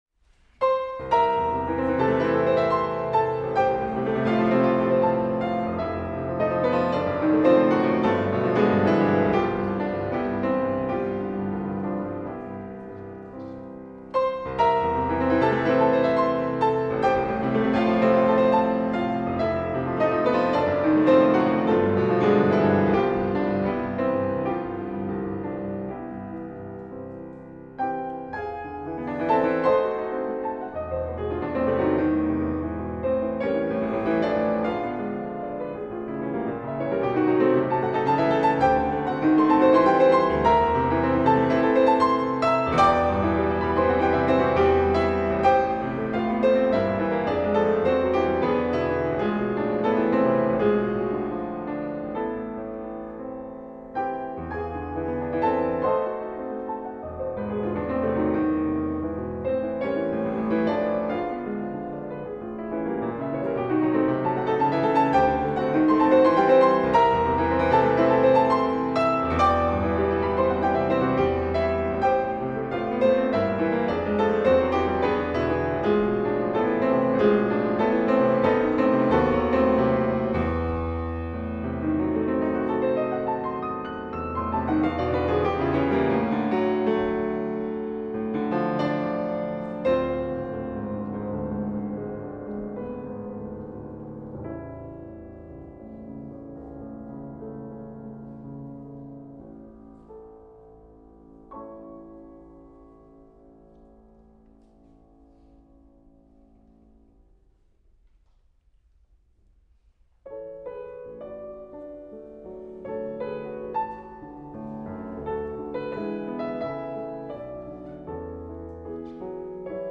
Instrument: Piano
Style: Classical
Audio: Boston - Isabella Stewart Gardner Museum
6-piano-pieces-op-118.mp3